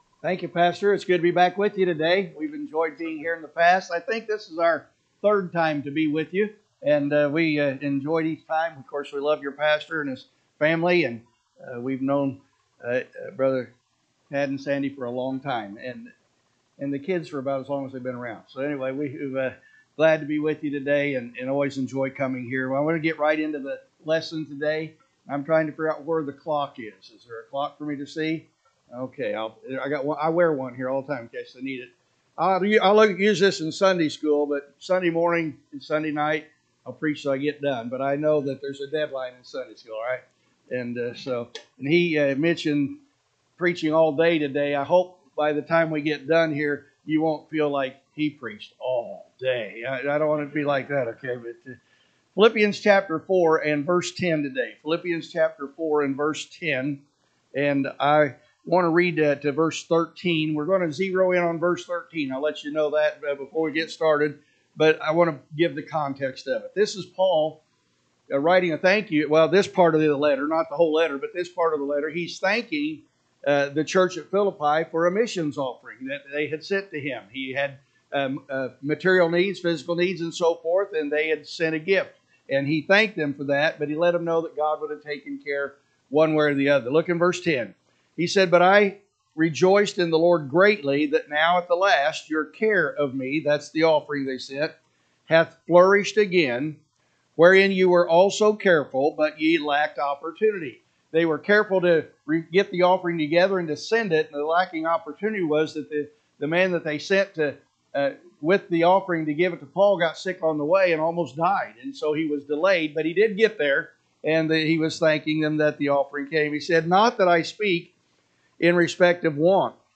August 31, 2025 Adult Bible Study